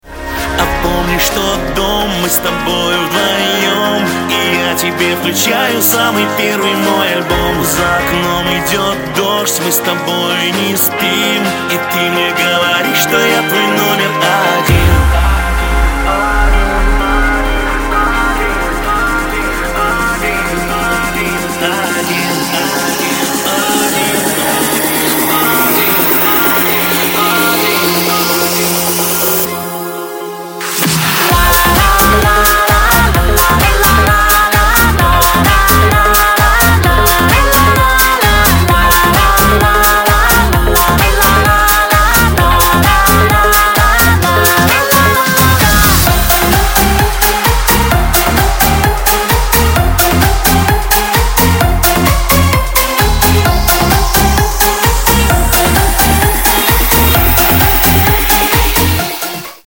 • Качество: 256, Stereo
поп
мужской вокал
dance